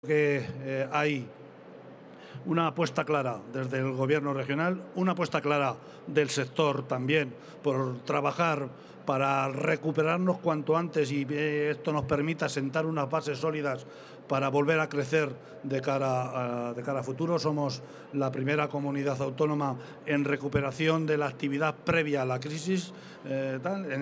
>> El vicepresidente autonómico ha destacado, en FITUR, los buenos datos con los que Cuenca cierra el 2021